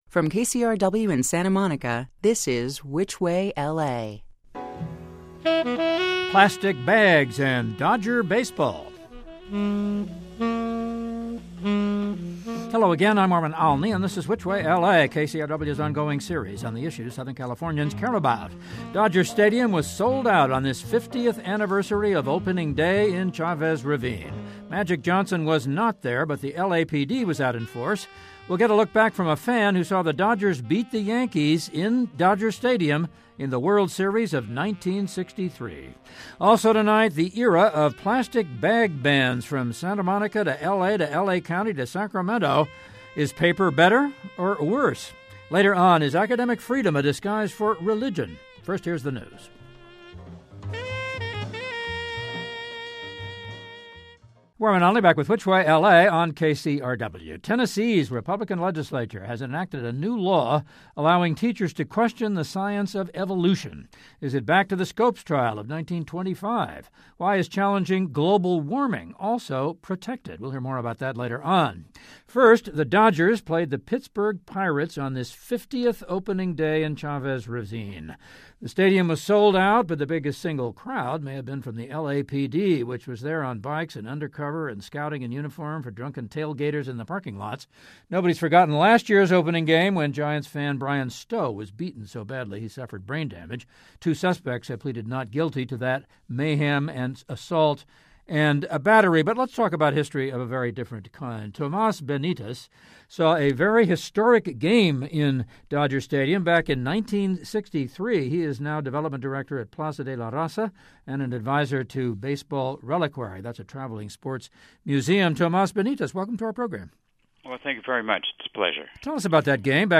Dodger Stadium was sold out on this 50th anniversary of opening day in Chavez Ravine. We hear from a fan who saw the Dodgers beat the Yanks in the 1963 World Series.